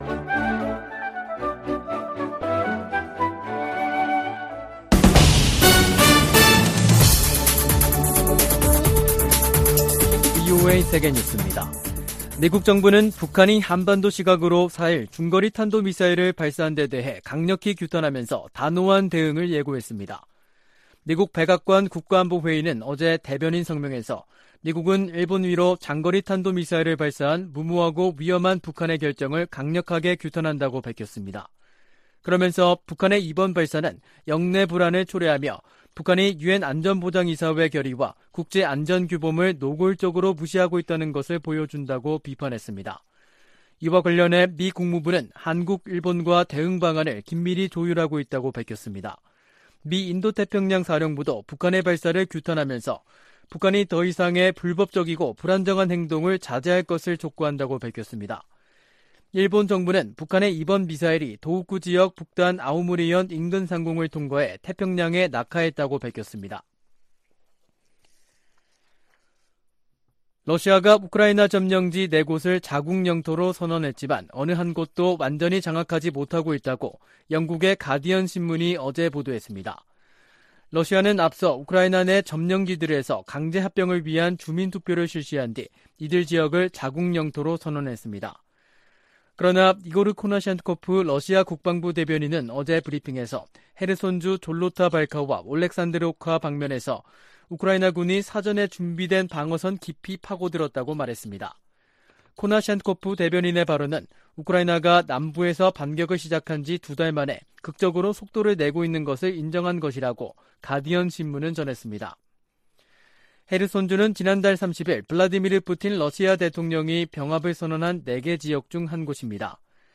VOA 한국어 간판 뉴스 프로그램 '뉴스 투데이', 2022년 10월 4일 3부 방송입니다. 북한이 4일 일본열도를 넘어가는 중거리 탄도미사일(IRBM)을 발사했습니다.